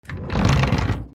/ K｜フォーリー(開閉) / K05 ｜ドア(扉)
引き戸 勢いよく